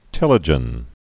(tĕlə-jən)